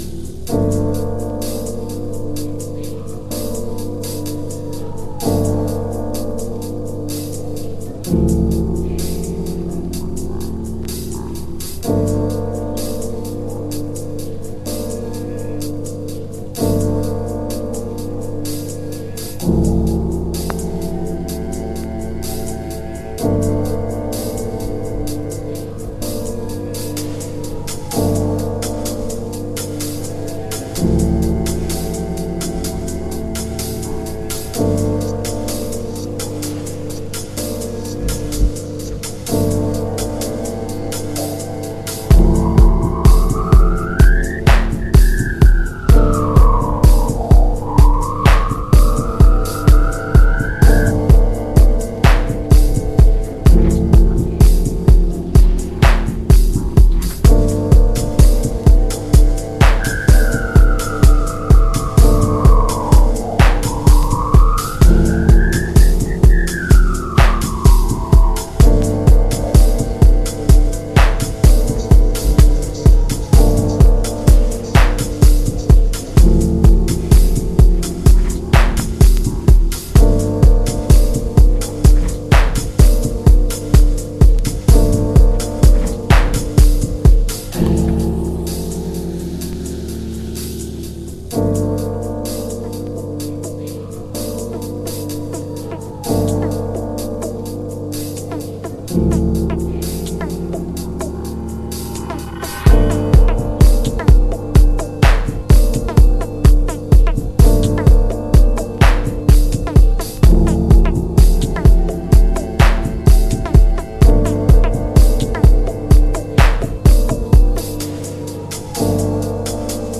情景的なシンセやエフェクトなのですが、ファニーな音も入っているのでオモロなところに飛ばしてくれるディープハウス。